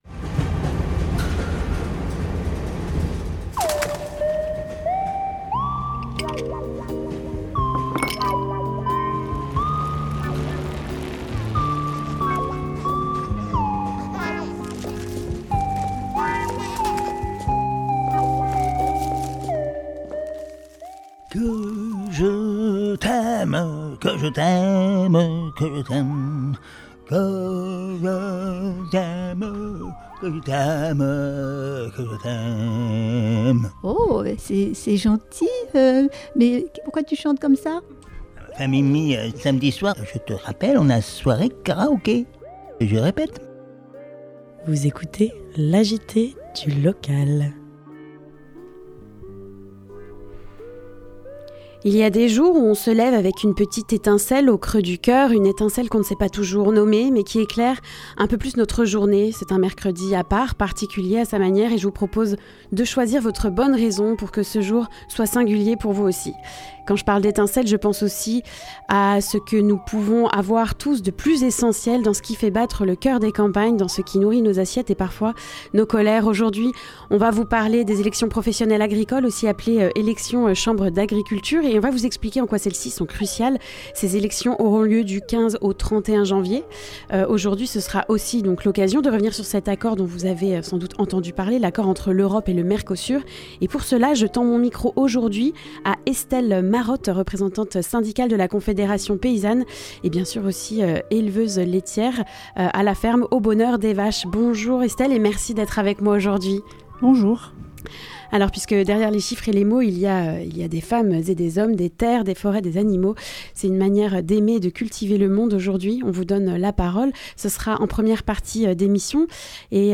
Le reportage nous fait découvrir le café associatif, Le p'tit Campo à Champgenéteux dans le nord Mayenne.
La chronique de l'UFC Que Choisir de la Mayenne